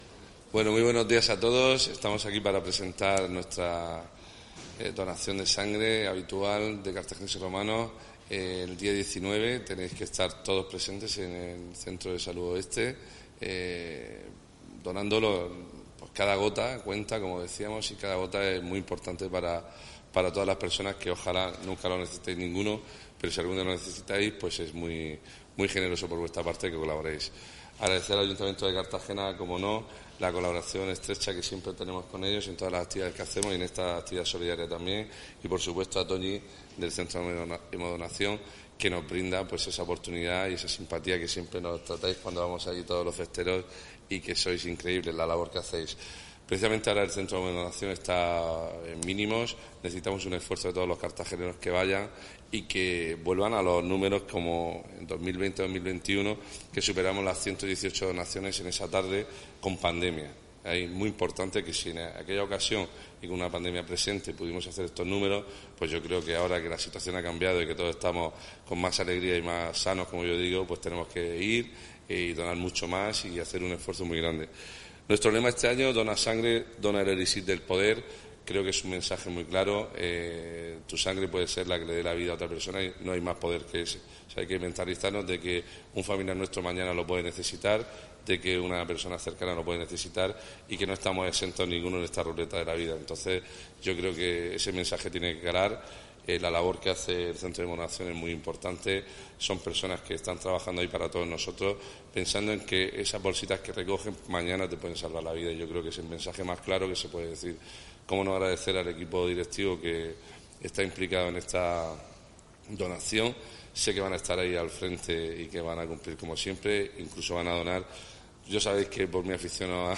Enlace a Presentación de la XVIII Batalla por la vida de Carthagineses y Romanos